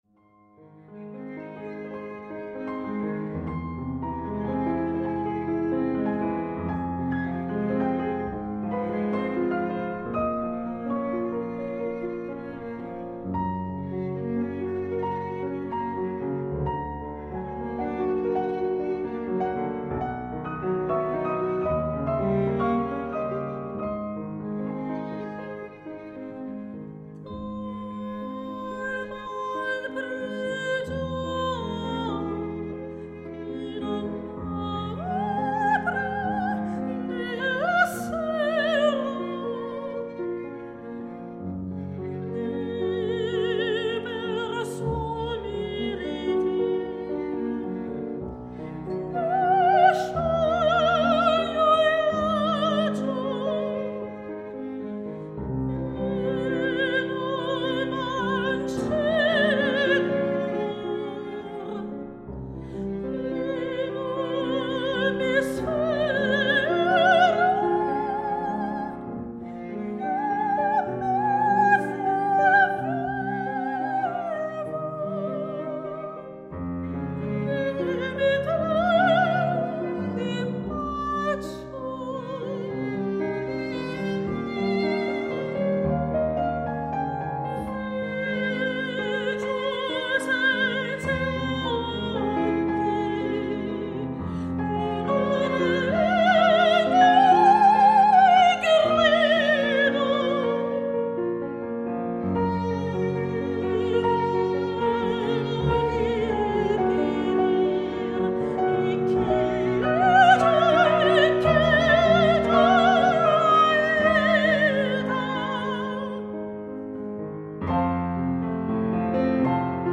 Un magnifique lied
pour soprano, alto et piano